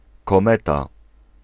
Ääntäminen
Ääntäminen France: IPA: /kɔ.mɛt/ Haettu sana löytyi näillä lähdekielillä: ranska Käännös Ääninäyte Substantiivit 1. kometa {f} Suku: f .